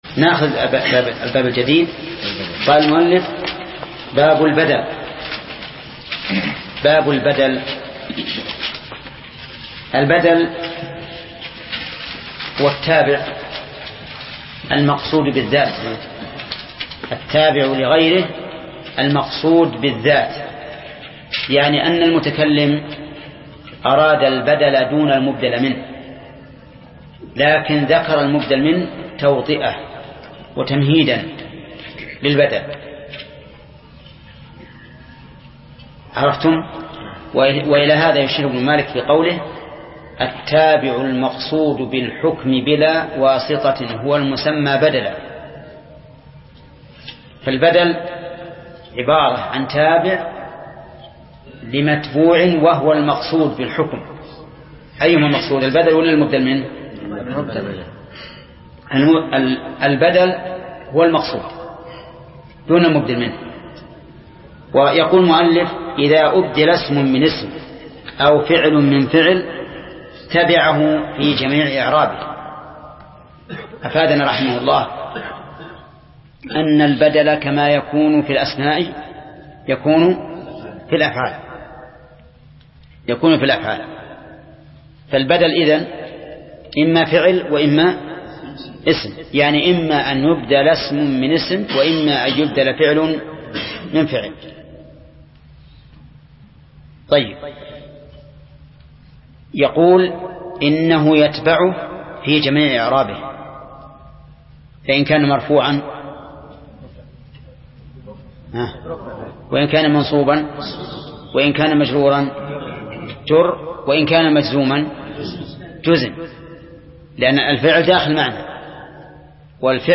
درس (17) : شرح الآجرومية : من صفحة: (347)، قوله: (البدل).، إلى صفحة: (367)، قوله: (باب المفعول به).